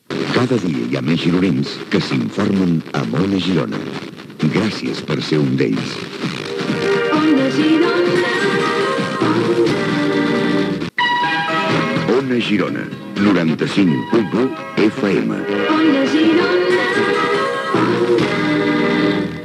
Indicatiu de l'emissora i careta d'Ona Girona Notícies.
Dos indicatius de l'emissora.